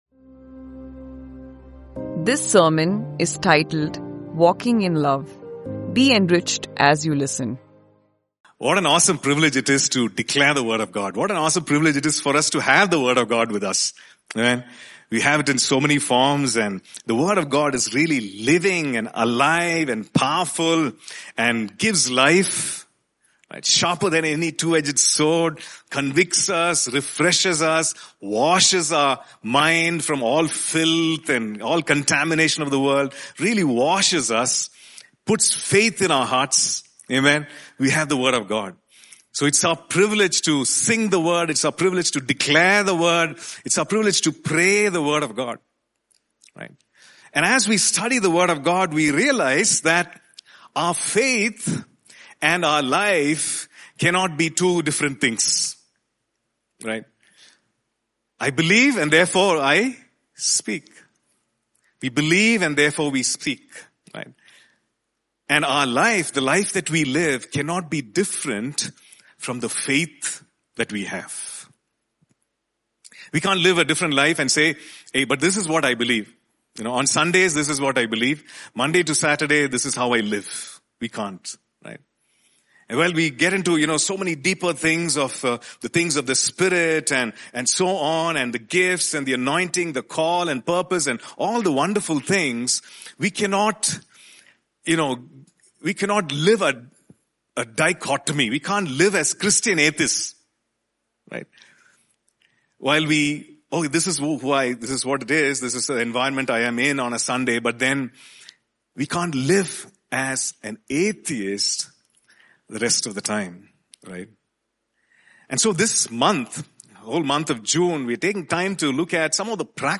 In this sermon we talk about one of the most important commandments given to us, to love others as Christ Himself has loved us. We review the 16 characteristics of love as mentioned in 1 Corinthians 13:4-8, address the important issue of love, correction and pain, and point us to Christ as our standard.